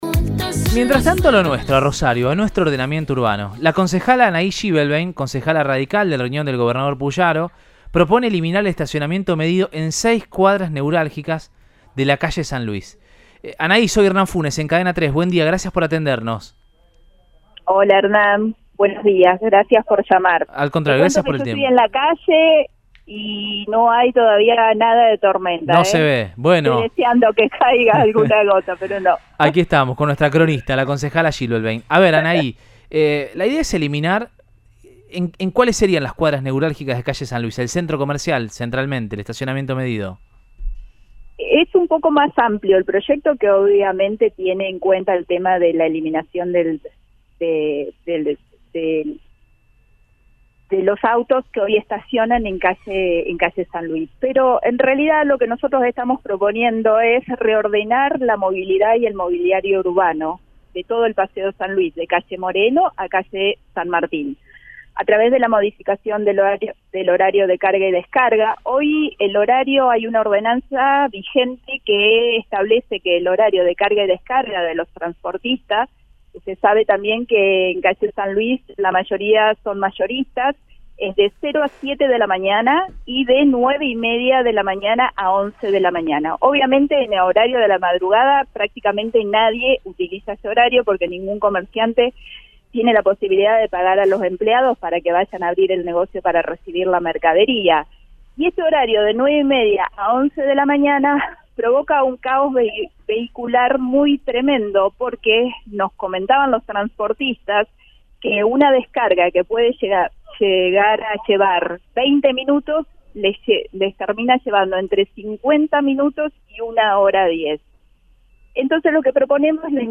El proyecto es de autoría de Anahí Schibelbein, concejal de la Unión Cívica Radical (UCR), que en Siempre Juntos, por Cadena 3 Rosario, dio detalles.